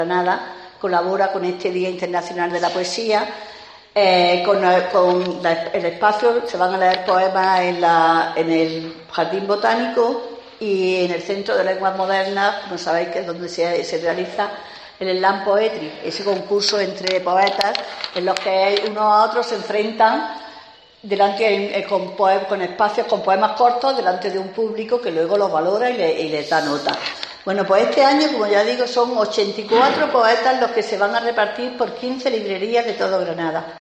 María Leyva, concejal de Cultura